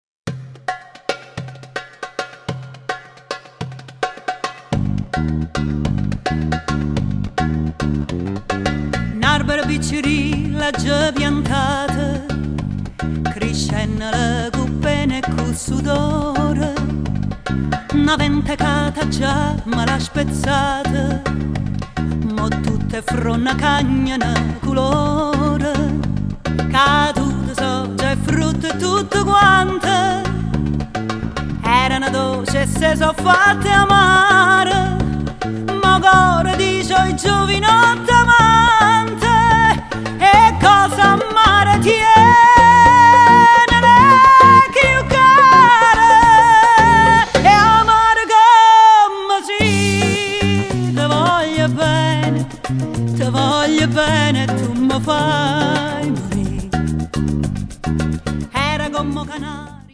vocals
electric basses, stick, midi
percussion
violin
viola
violoncello